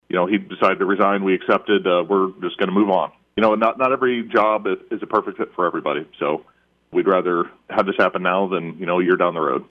Tuesday afternoon the City of Marshalltown sent out a press release stating that the City Administrator, Joe Gaa, has resigned. City Councilor Jeff Schneider joined the KFJB line yesterday to talk about why and what is next.